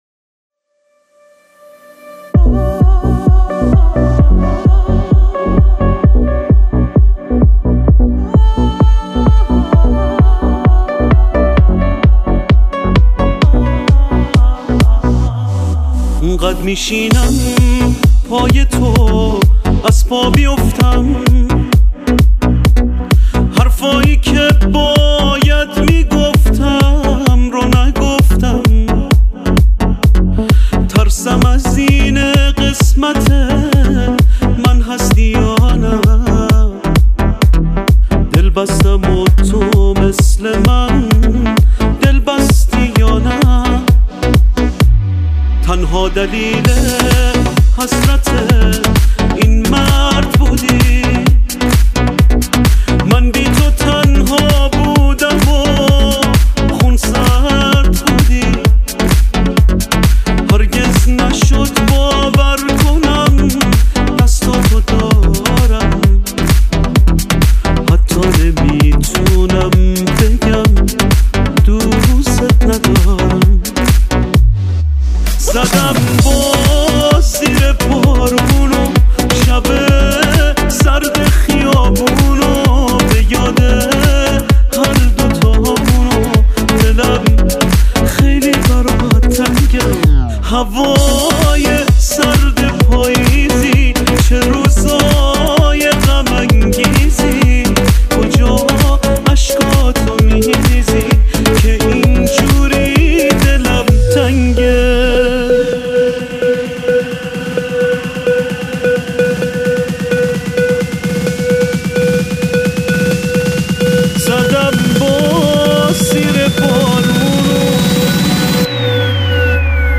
Download New Remix